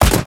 RifleShot02.wav